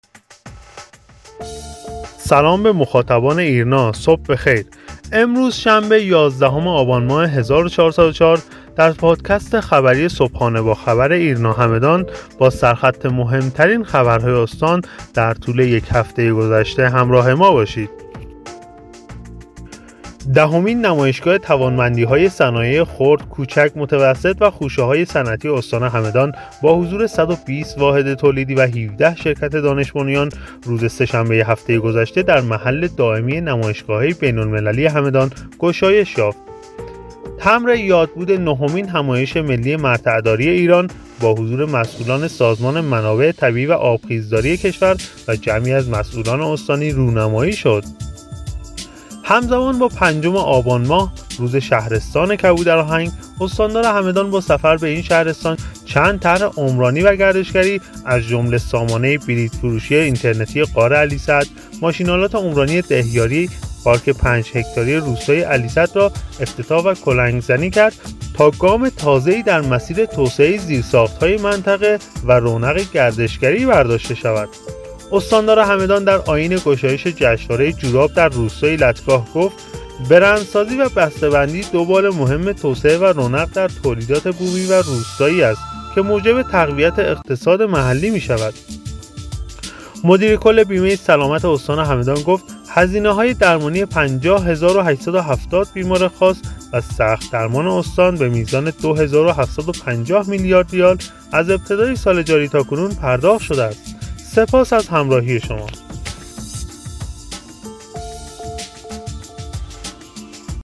خبرنامه صوتی (پادکست) صبحانه با خبر ایرنا همدان را هر روز ساعت هشت صبح دنبال کنید.